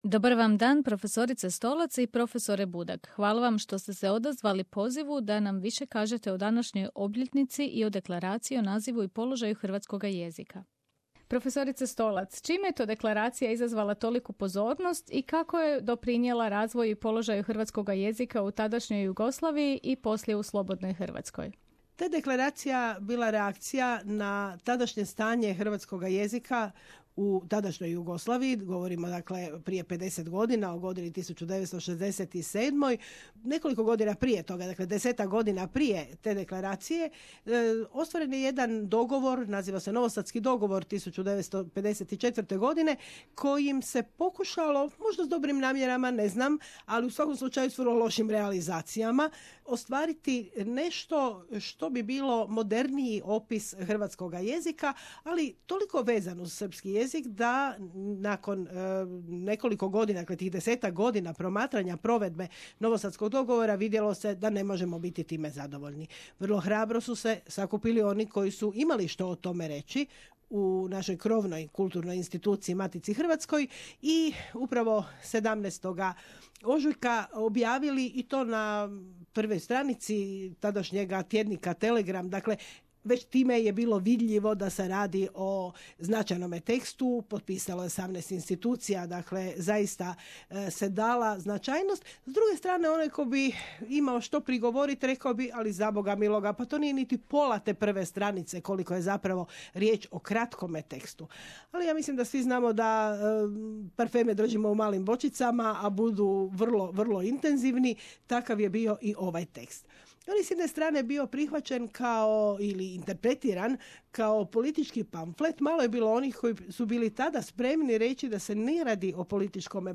Prije 50 godina, 17. ožujka 1967. godine, u zagrebačkom tjedniku Telegram objavljen je jedan od najvažnijih dokumenata o hrvatskome jeziku. Riječ je o Deklaraciji o nazivu i položaju hrvatskog književnog jezika koju su potpisali ugledni pojedinci i ustanove na čelu s Maticom hrvatskom. Povodom te važne obljetnice razgovarali smo s dvoje uvaženih hrvatskih jezikoslovaca